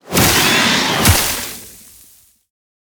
Sfx_creature_snowstalkerbaby_death_land_01.ogg